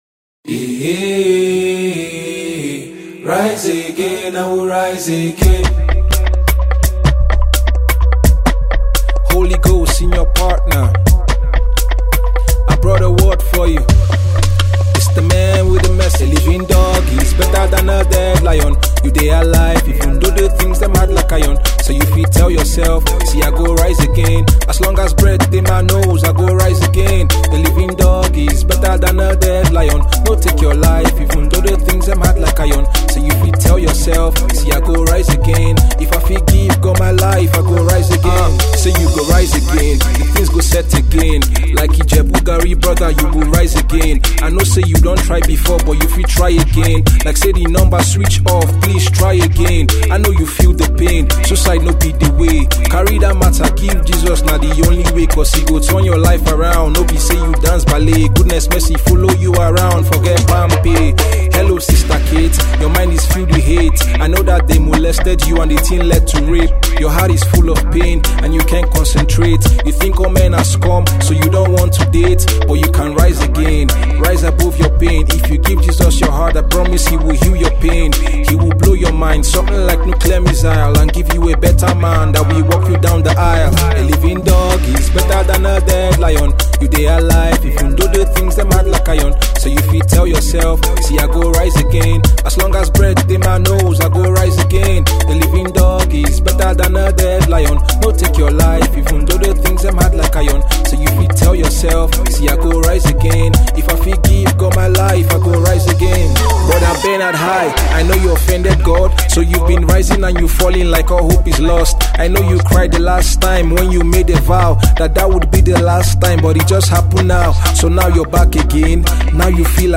Christian rap artist